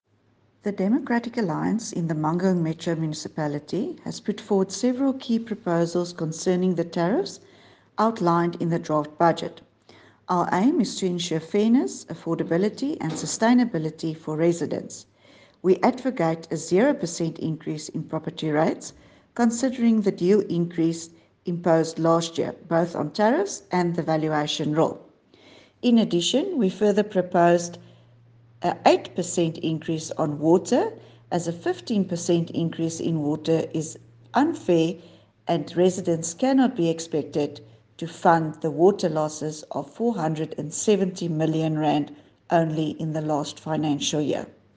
Afrikaans soundbites by Cllr Dulandi Leech and